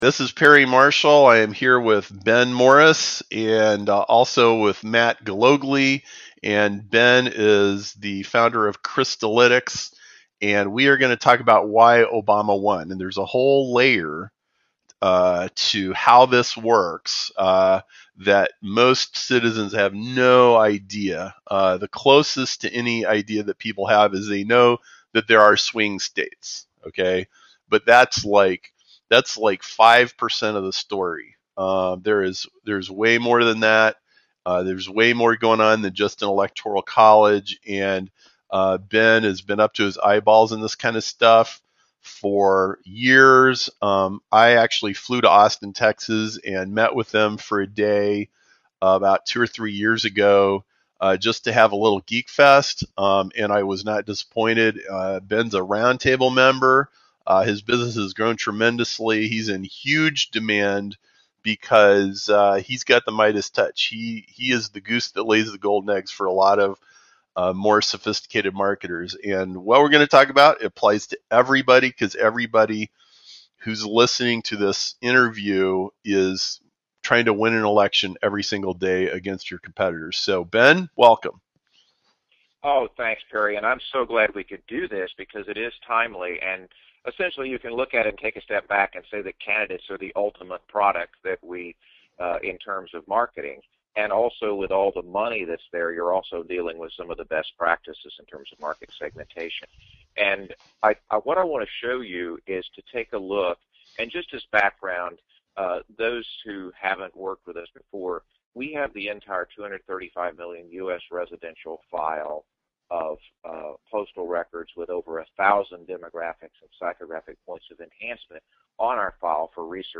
This conversation is highly relevant to YOU.